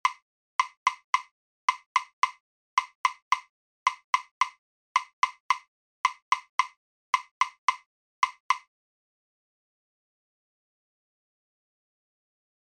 These might be played by the percussion instruments or rhythm section.
Cha Cha
Chacha-Rhythm.mp3